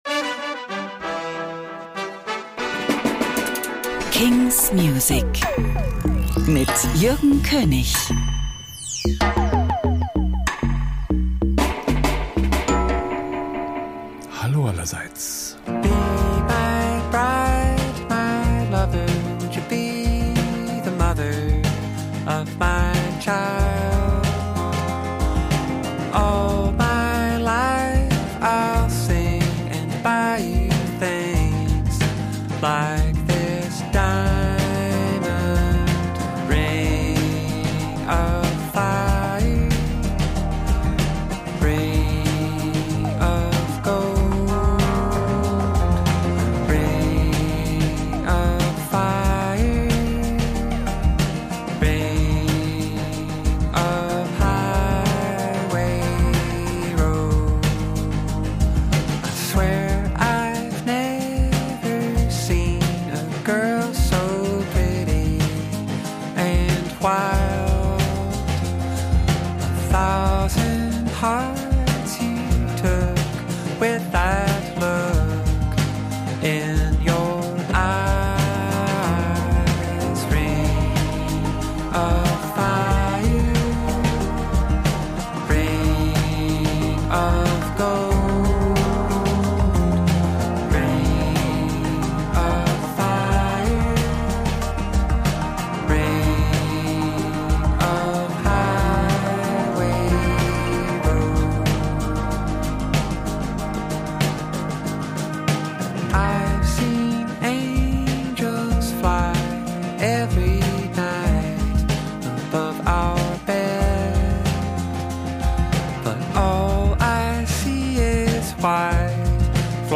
selection of brandnew indie & alternative releases